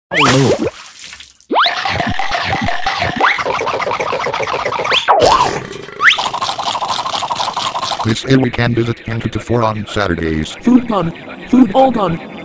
I made an mp3 file using Microsoft Sam and various sound effects to dub a comic chosen by a fan of my channel, which dedicates to these things so it was easy peasy